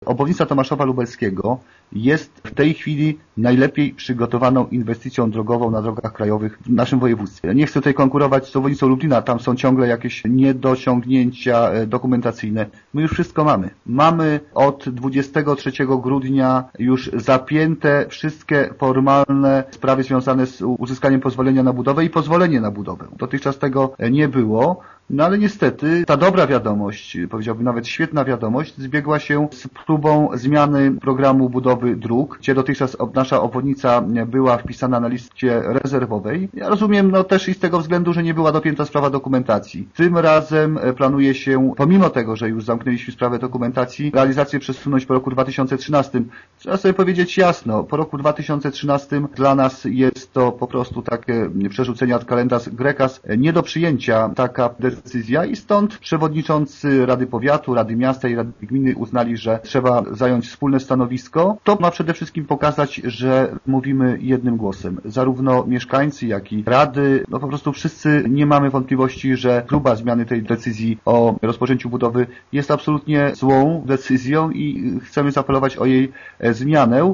„Zwołaliśmy nadzwyczajne posiedzenie, bo i sytuacja, związana z tą inwestycję jest nadzwyczajna” - mówi burmistrz Tomaszowa Wojciech Żukowski: